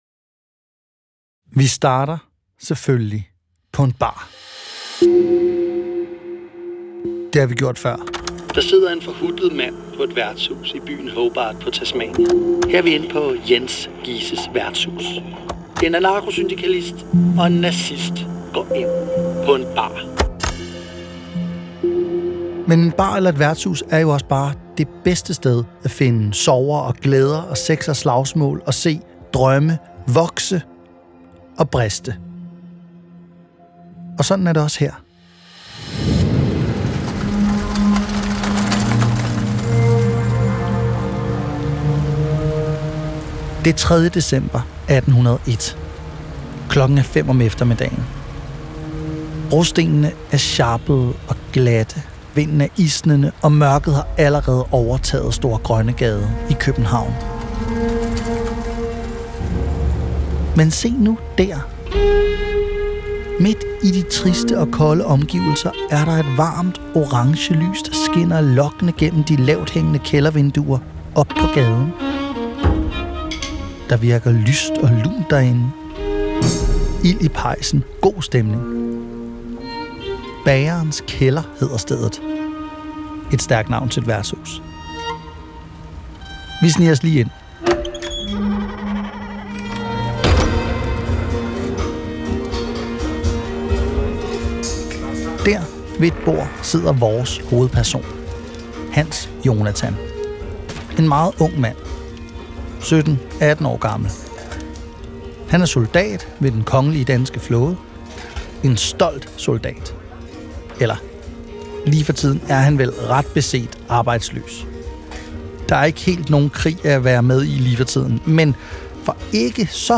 Det er actionfyldte lydfortællinger baseret på den vilde virkelighed.